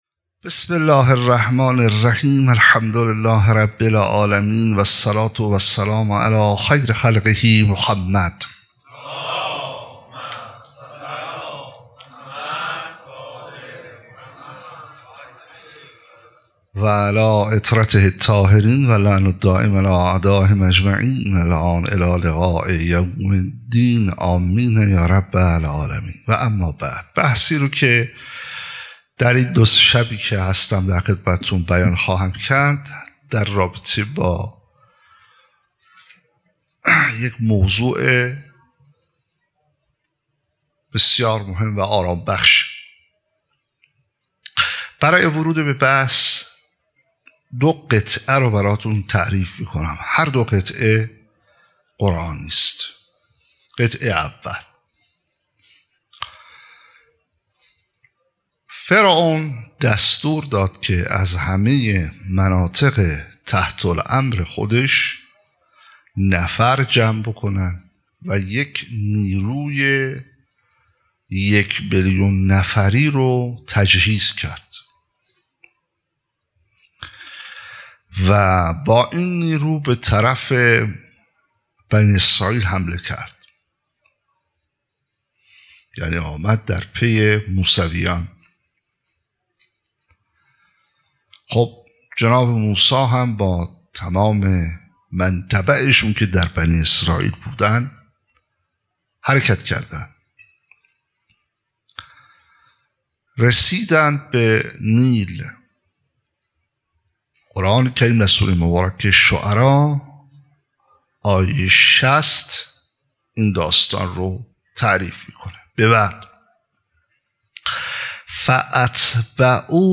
شب چهارم فاطمیه ۱۴۰۰ | هیأت میثاق با شهدا